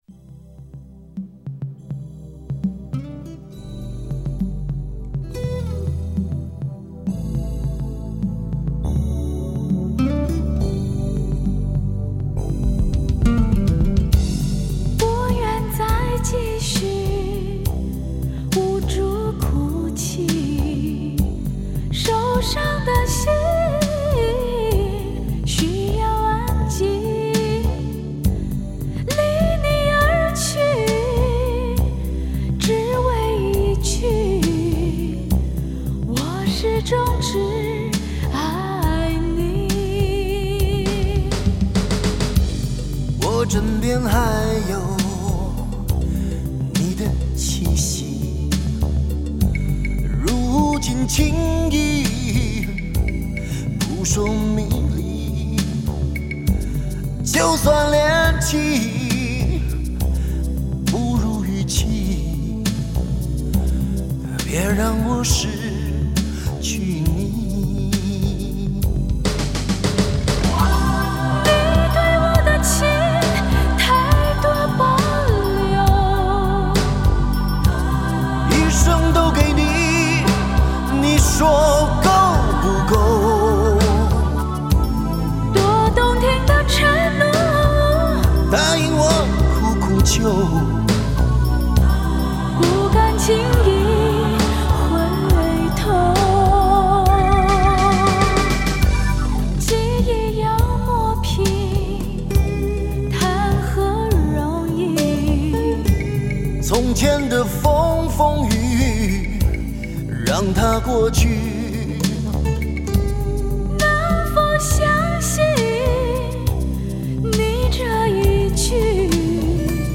男女对唱系列之08